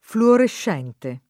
[ fluorešš $ nte ]